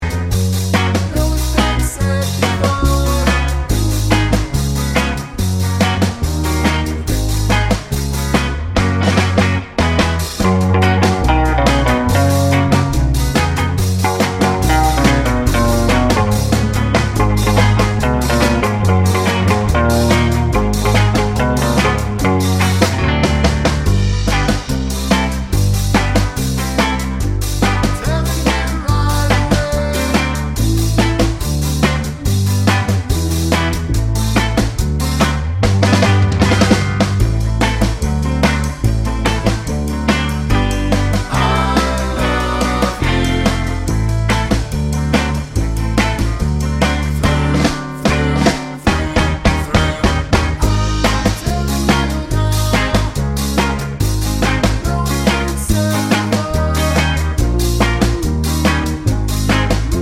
Two Semitones Down Pop (1960s) 2:08 Buy £1.50